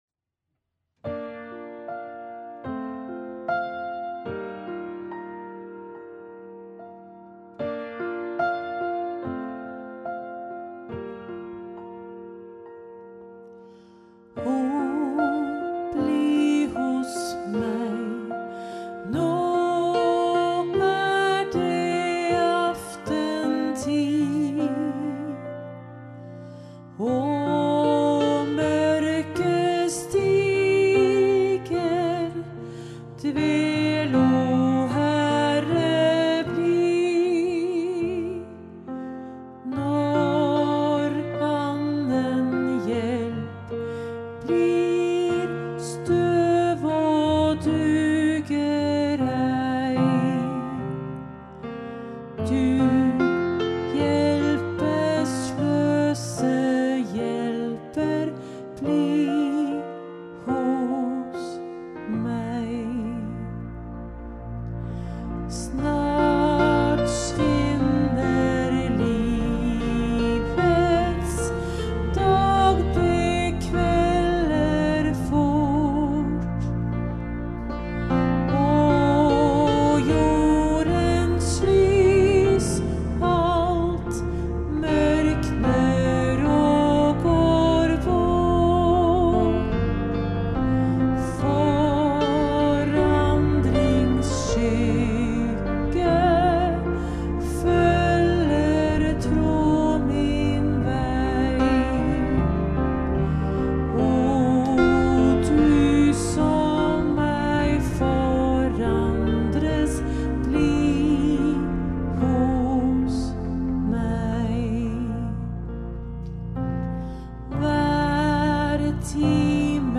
Musikk til begravelse - våre solister
messo-sopran
Hun har en stemme med nær og rolig klang og kan tilpasse seg flere ulike sjangere.